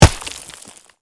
dirt01gr-converted.mp3